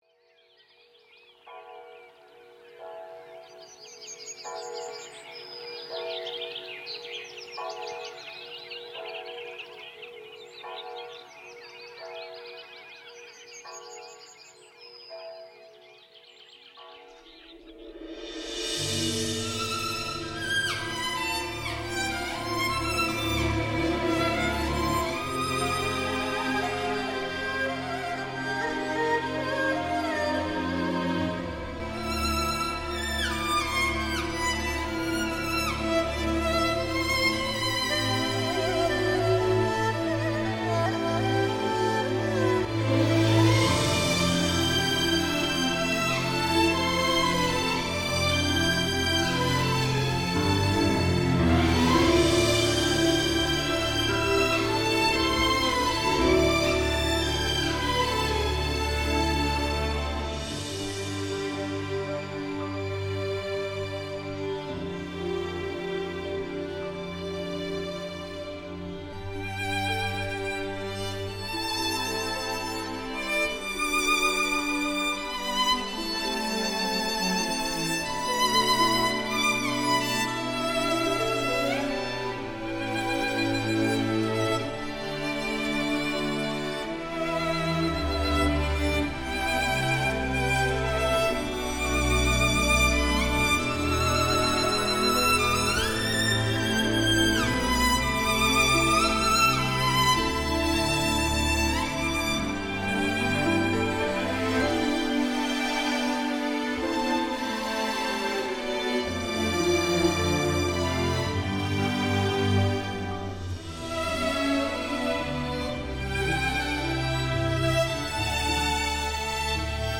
完美打造出顶级质感小提琴经典，